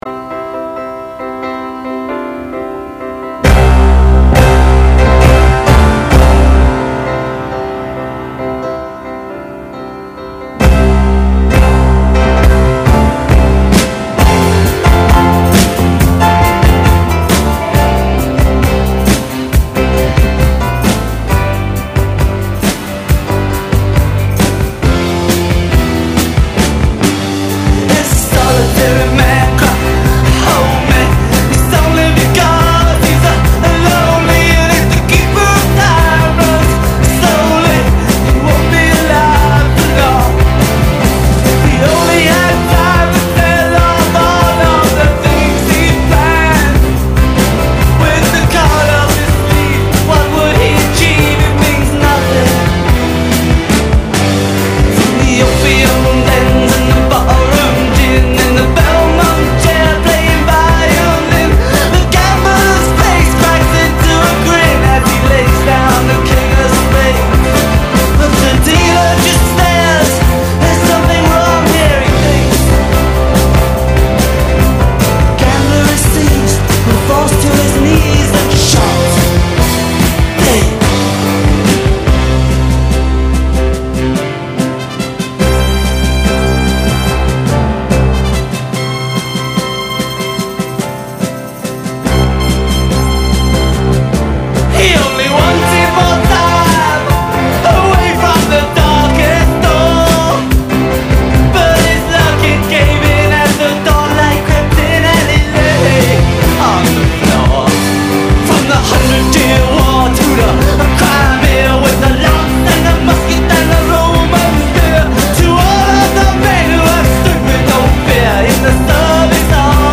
la caution reggae de cet épisode